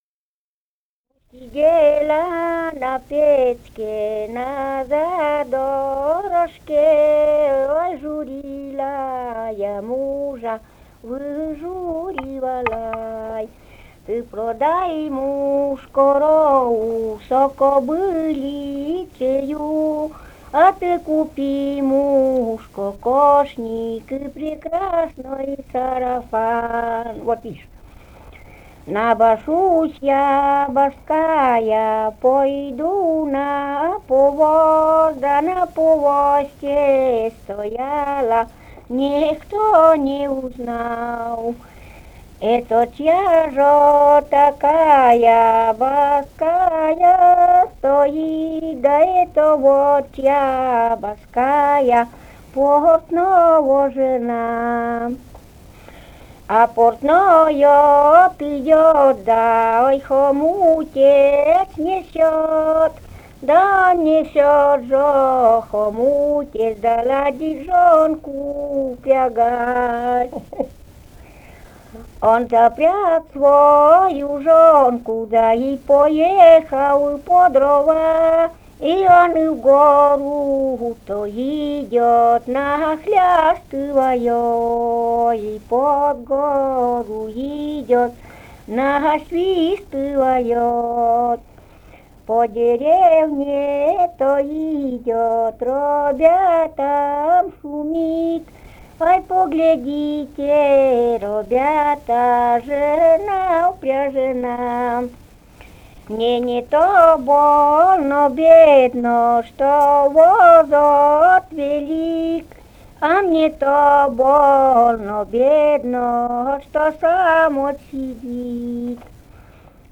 «Я сидела на печке» (лирическая).